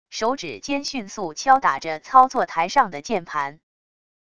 手指尖迅速敲打着操作台上的键盘wav音频